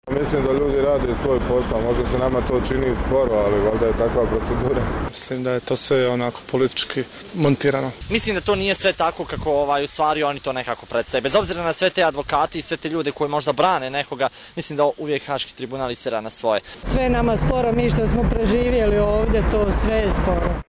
Anketa: Građani BiH o suđenjima za ratne zločine